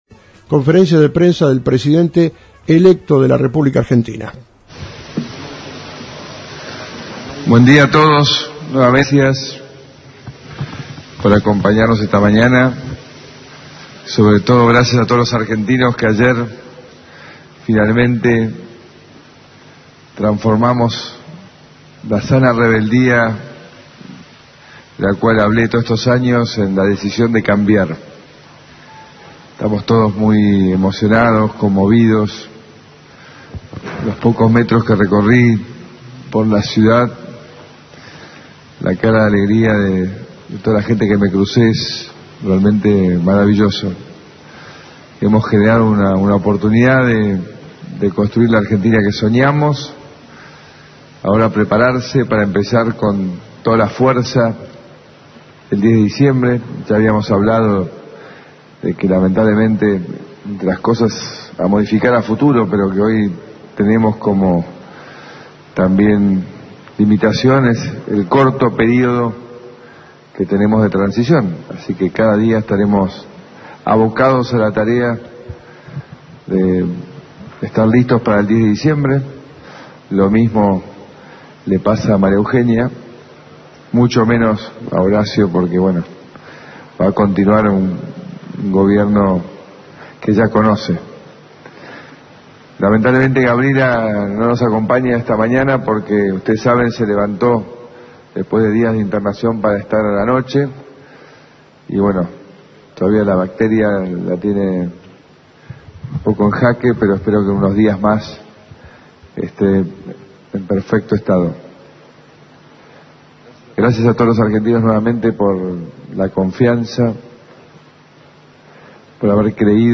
Conferencia de Prensa del Presidente Electo Mauricio Macri :: Radio Federal Bolívar